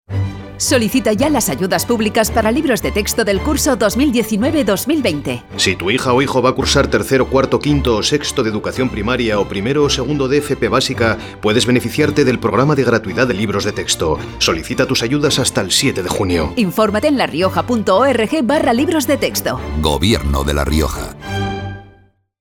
Elementos de campaña Cuñas radiofónica Cuña de 20" Inserciones en medios impresos Ejemplo de inserción en medio impreso.